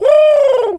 pigeon_call_angry_11.wav